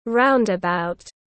Roundabout /ˈraʊnd.ə.baʊt/